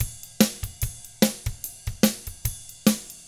146ROCK T2-L.wav